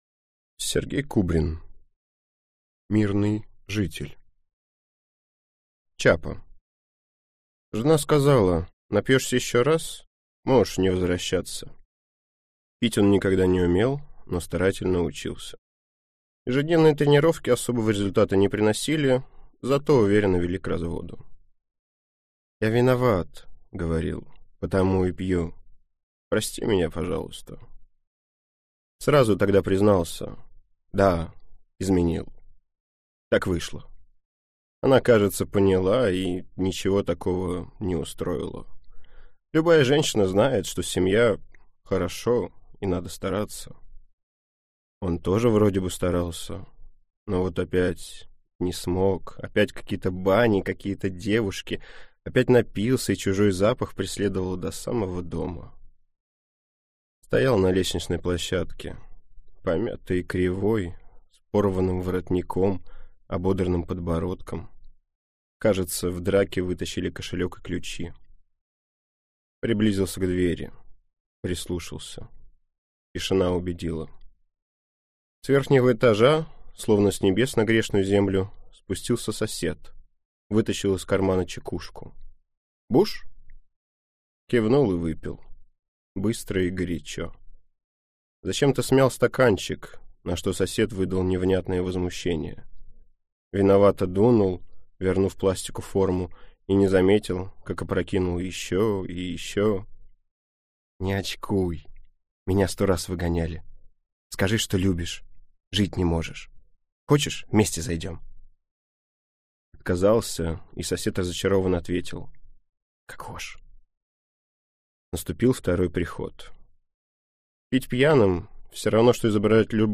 Аудиокнига Мирный житель | Библиотека аудиокниг